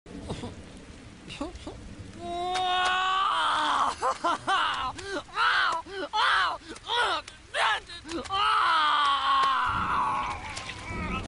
Stitch crying sound effects free download